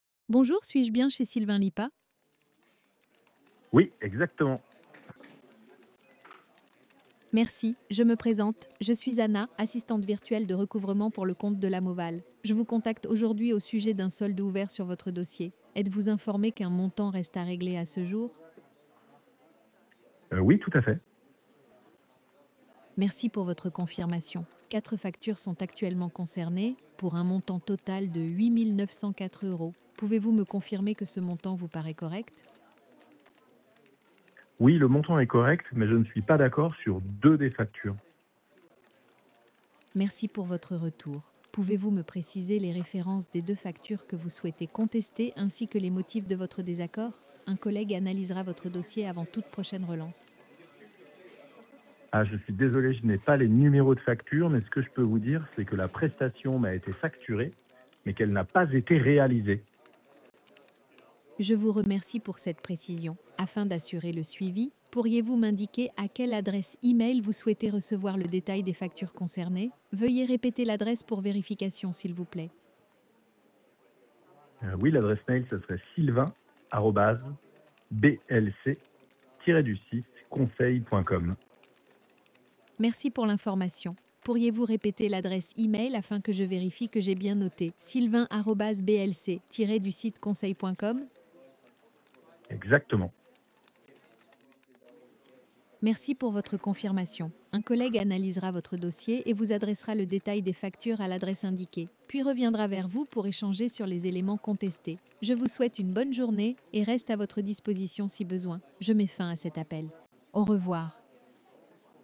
Découvrez ci-dessous 3 enregistrements d’appels IA que nous avons testés.